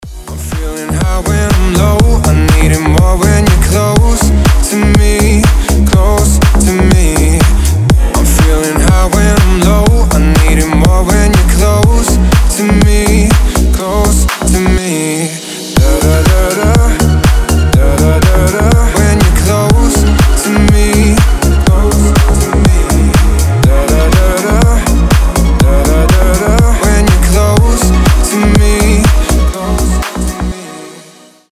• Качество: 320, Stereo
мужской голос
deep house
Dance Pop